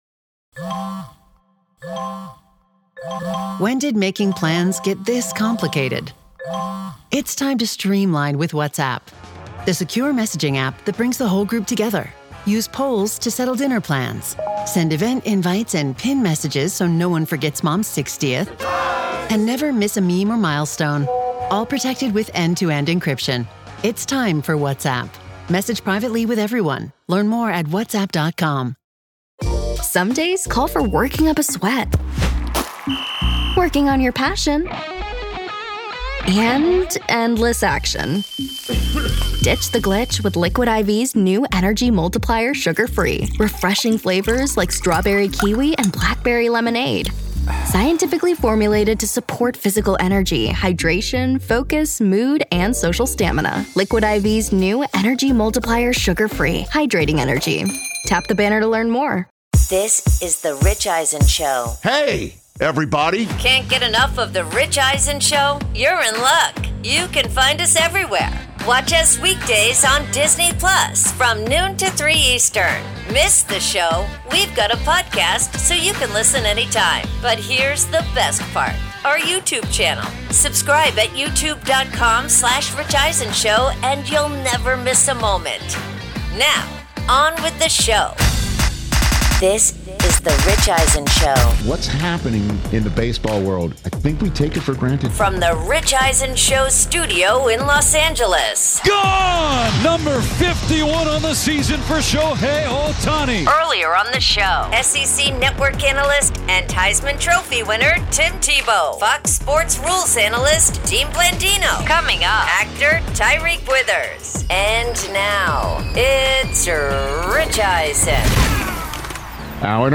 Hour 3: NFL Week 3 ‘Higher Register,’ plus ‘Him’ Star Tyriq Withers In-Studio